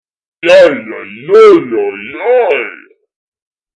声乐 语音 语音 " 男声低语 对不起 英语 已处理
描述：男声低语对不起 英文 已处理 接口：focusrite scarlett 2i2Alesis firewire io14 麦克风：Rode NT1000SE 2200 AAKG C1000sRadioshack PZM压力区麦克风X2Optimus 333022边界麦克风X2Optimus 333017电容式麦克风现实驻极体电容式立体声麦克风33919定制PZM Panasonic定制48volt幻象单元XSPlock音频C 009ERF边界麦克风单声道立体声Electret电容麦克风ECM99 AOktaver IIMK55Oktava mke2AKG D95sBeyer Dynamic M58各种老式麦克风 便携式装置：Olympus VN8600PCZOOM H2 其他设备：Phonic MU802 MixerBehringer Behringer UCONTROL UCA222Korg Toneworks AX100G各种吉他踏板 软件：ReaperAudacity
标签： 讲话 谈话 声音 对不起 英语 人力 演讲 歌唱 说话 言语
声道立体声